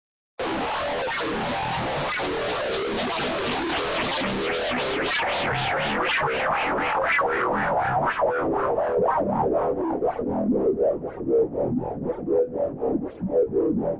描述：treated synthesiser riff (down)
标签： electronica riff soundscape synth
声道立体声